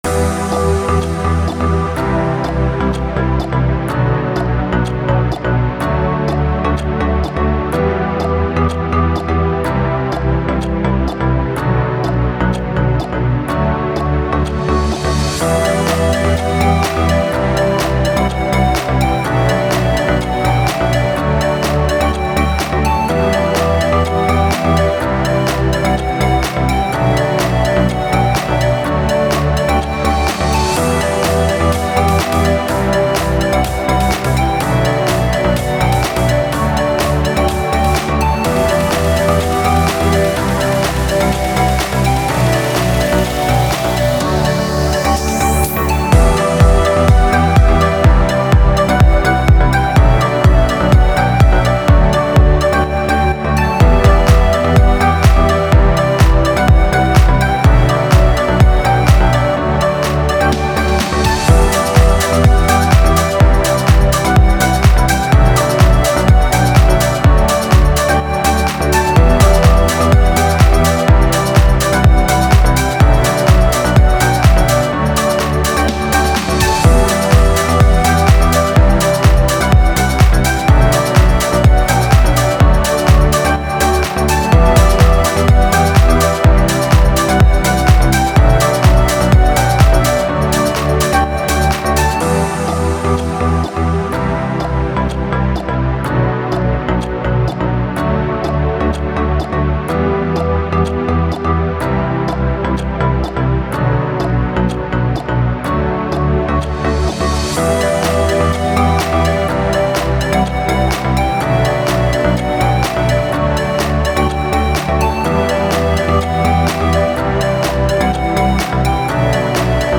У нас тут резкий переход от зимы к лету- только недавно ходили в куртках и пуховиках, теперь все в футболках Немного попсовой музычки к началу лета) Неплохой инструментальчик